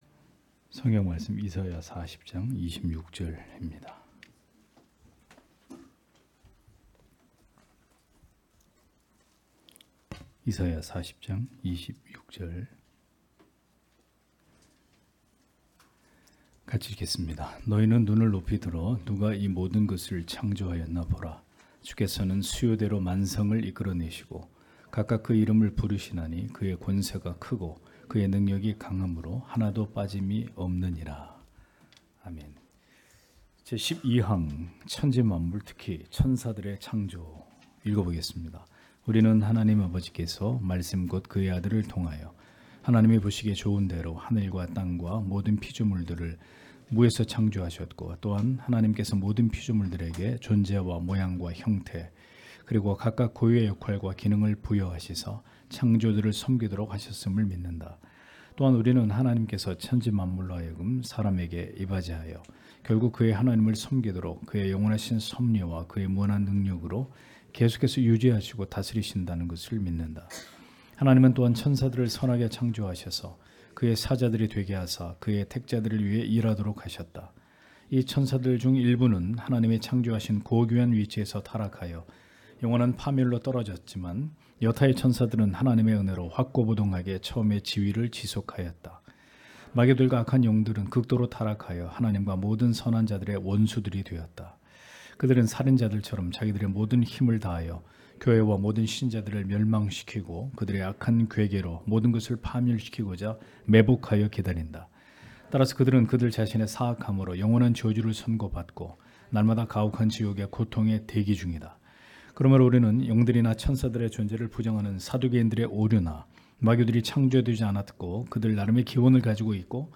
주일오후예배 - [벨직 신앙고백서 해설 12] 제12항 천지만물, 특히 천사들의 창조 (이사야 40장 26절)
* 설교 파일을 다운 받으시려면 아래 설교 제목을 클릭해서 다운 받으시면 됩니다.